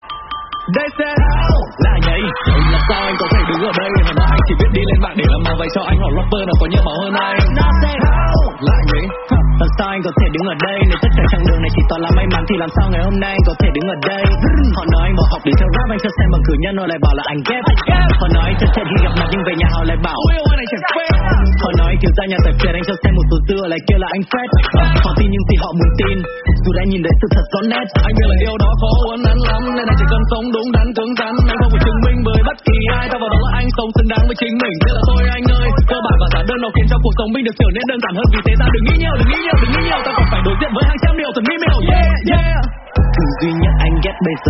Rap Việt